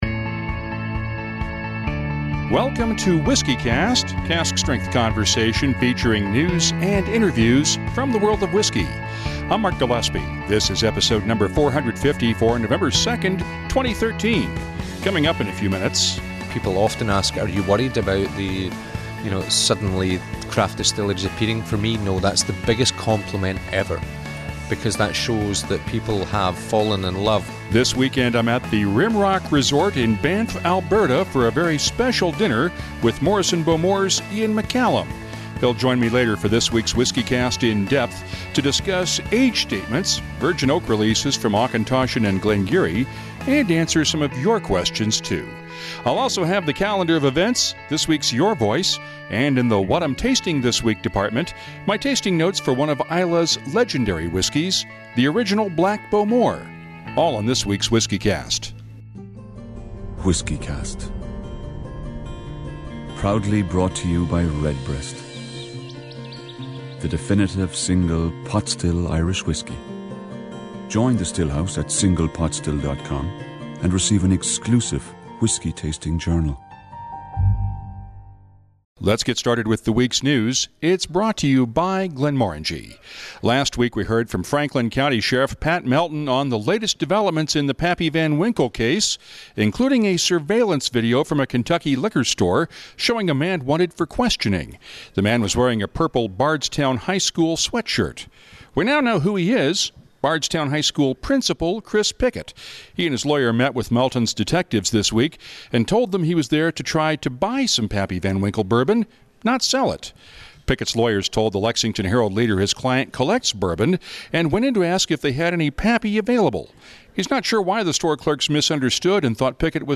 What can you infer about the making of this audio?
This week, we’re in Banff, Alberta for a very special Bowmore weekend in the heart of the Canadian Rockies.